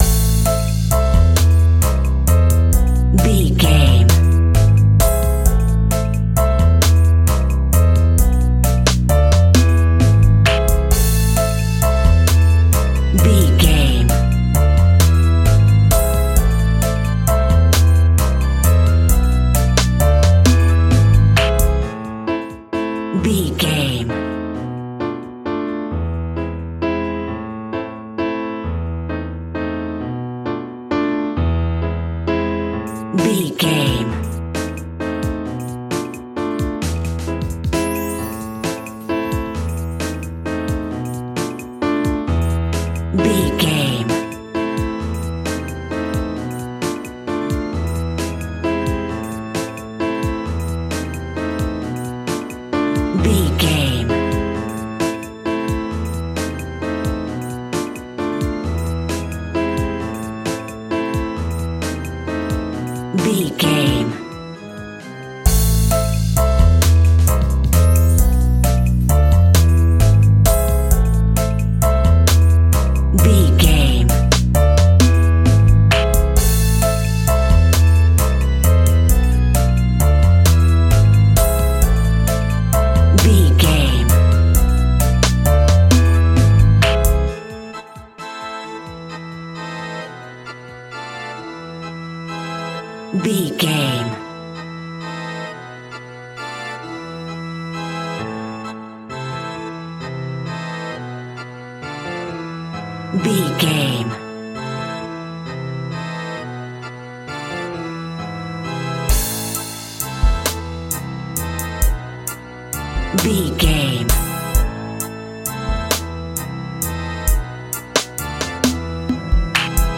In-crescendo
Thriller
Aeolian/Minor
scary
tension
ominous
dark
suspense
eerie
strings
brass
percussion
violin
cello
double bass
cymbals
gongs
taiko drums
timpani